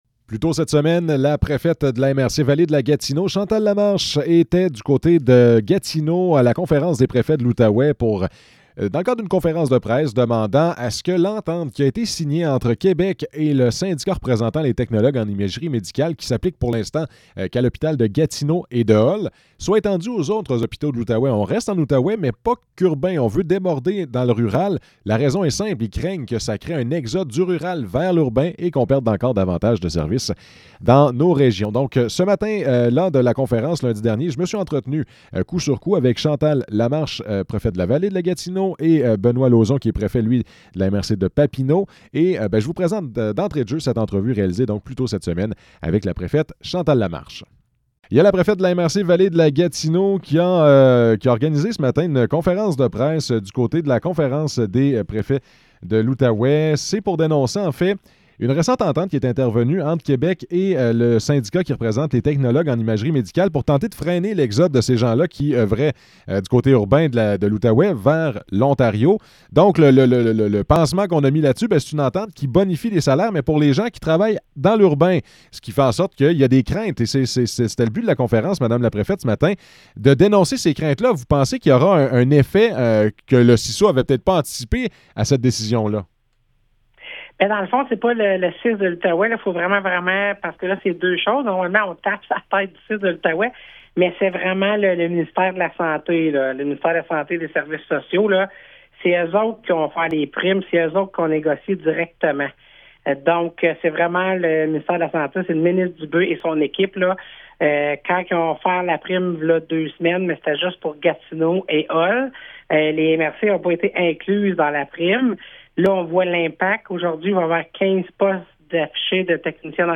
Entrevue avec Chantal Lamarche et Benoit Lauzon